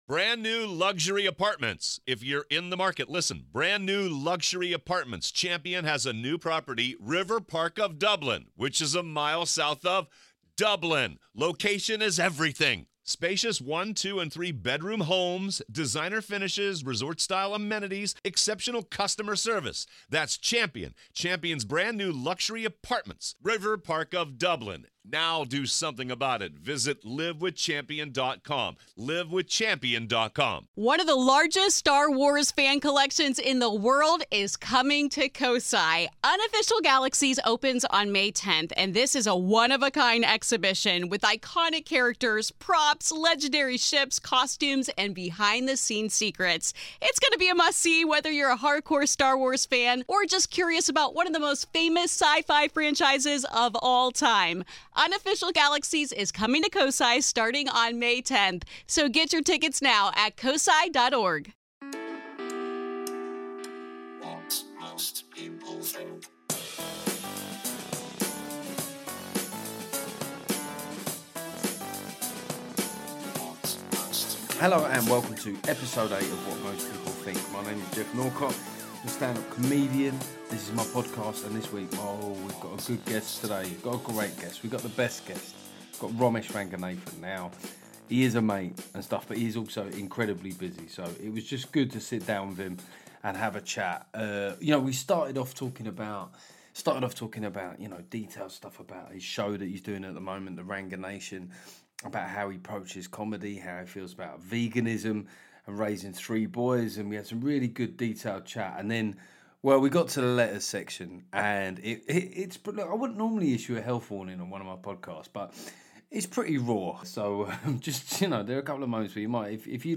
After a quick catch-up on Remain’s bizarre Euro Election logic, I have a fun chat with Romesh. We talk about his new TV show, the cranky left, his issue with militant vegans and the challenge of raising three boys.
Keep an eye on volume if listening without headphones.